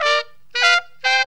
HORN RIFF 2.wav